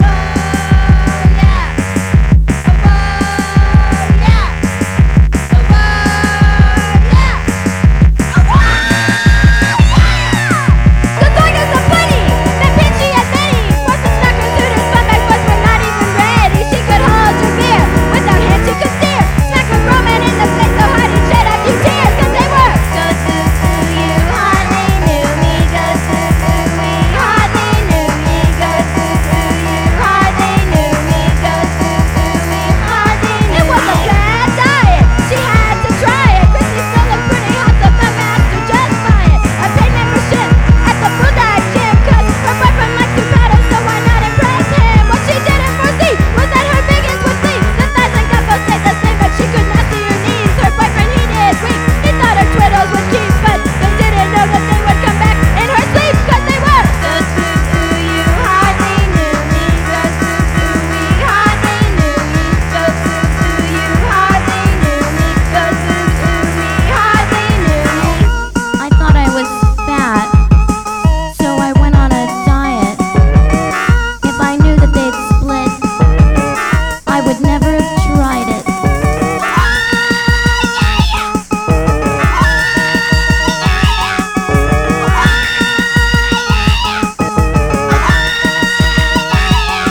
00'S PUNK
ザラついたギター・サウンドと、血管浮き出しながらシャウトするパワフルな魅力たっぷりの